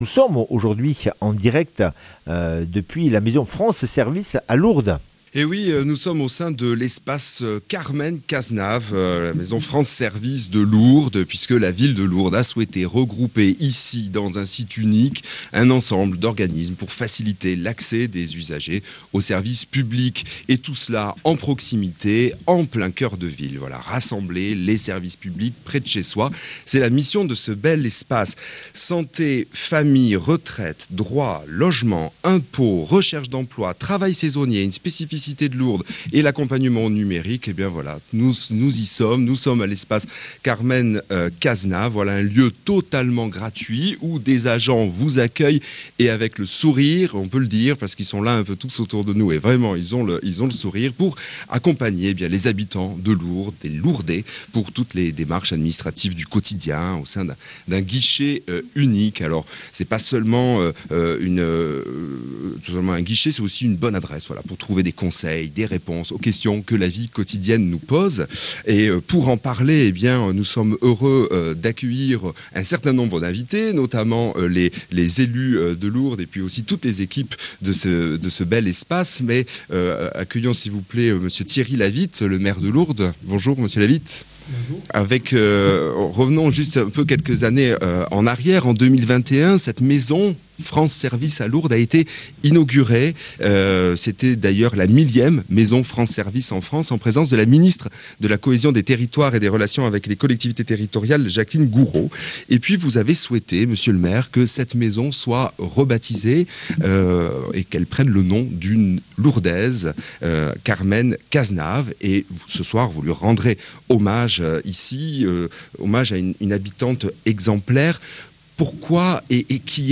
Immersion en direct de l’espace Carmen Cazenave, France Services de Lourdes, en faveur d’une plus grande proximité des services publics auprès des citoyens. Santé, solidarité, jeunesse, accompagnement au numérique... Rencontre avec le maire de Lourdes, les élus et les équipes d’accueil.